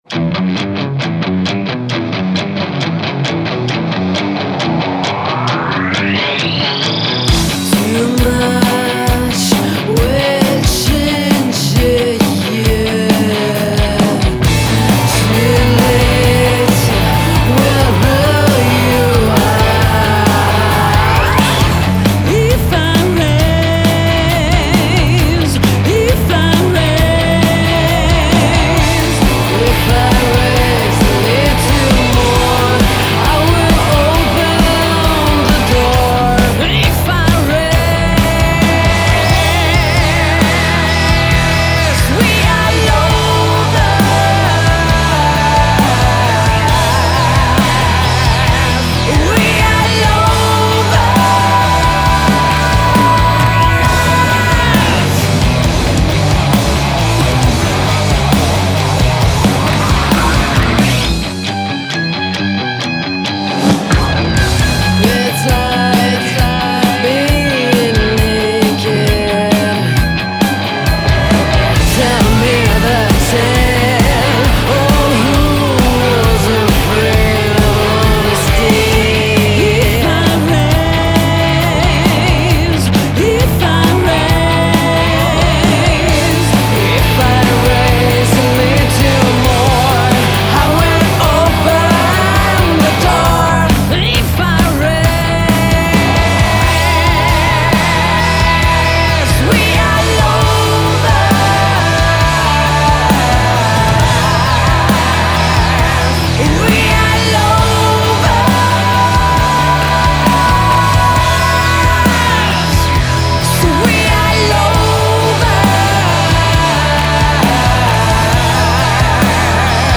Genere: Alt. Rock.